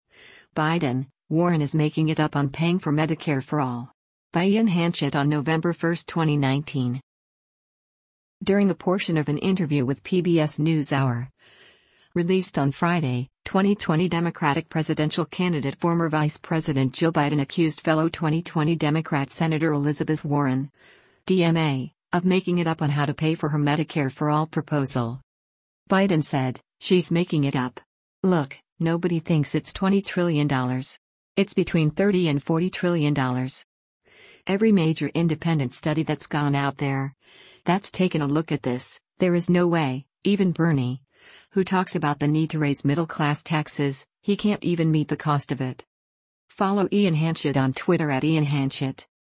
During a portion of an interview with “PBS NewsHour,” released on Friday, 2020 Democratic presidential candidate former Vice President Joe Biden accused fellow 2020 Democrat Senator Elizabeth Warren (D-MA) of “making it up” on how to pay for her Medicare for all proposal.